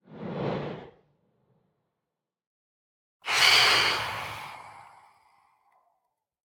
Minecraft Version Minecraft Version 1.21.5 Latest Release | Latest Snapshot 1.21.5 / assets / minecraft / sounds / mob / phantom / swoop3.ogg Compare With Compare With Latest Release | Latest Snapshot
swoop3.ogg